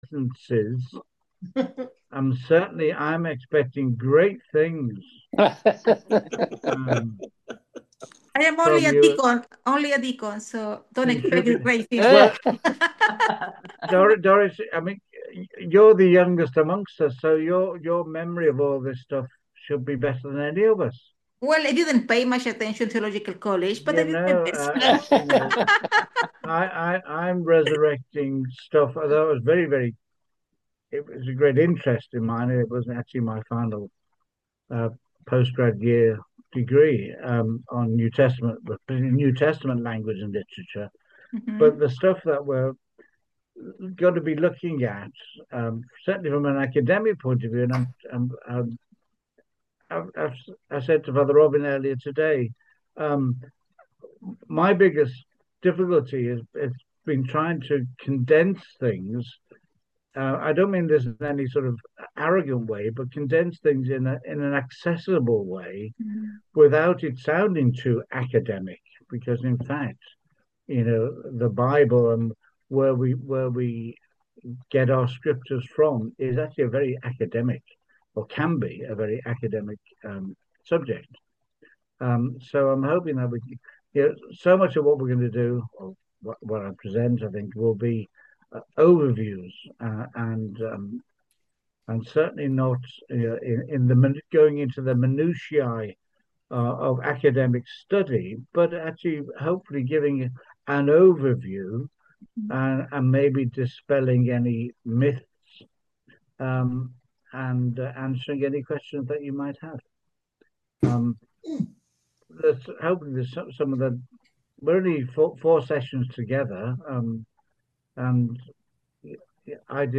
The format will be via ZOOM, with teaching and discussions - each session will close with prayer and anyone is welcome to join using the link below